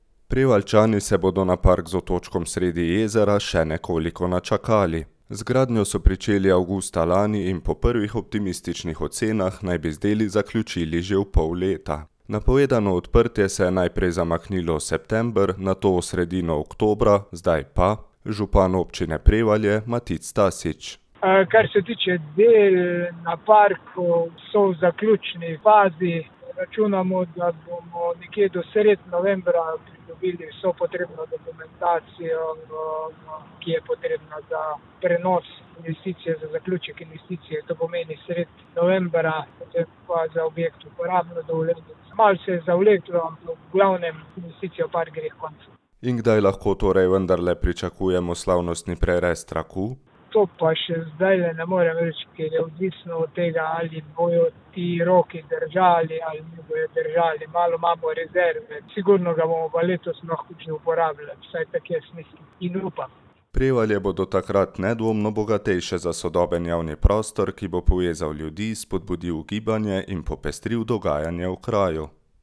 Napovedano odprtje se je najprej zamaknilo v september, nato v sredino oktobra, zdaj pa na občini upajo, da bo za slavnostni prerez trku nared do konca leta, je povedal župan Občine Prevalje Matic Tasič.